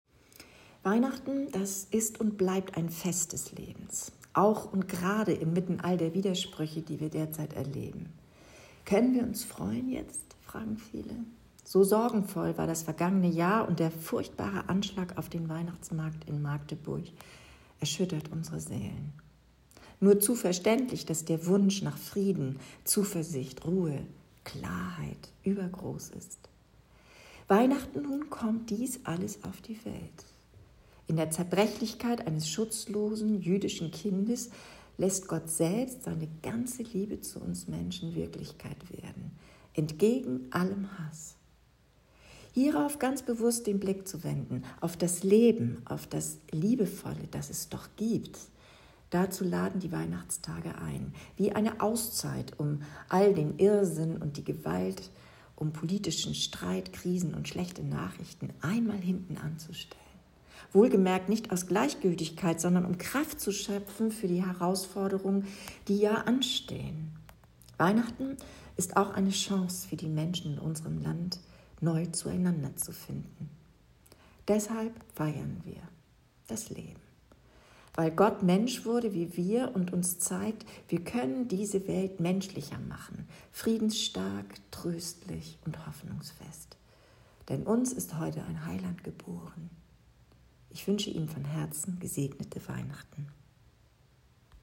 Die Botschaft der Bischöfin als Audio